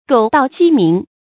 狗盗鸡鸣 gǒu dào jī míng
狗盗鸡鸣发音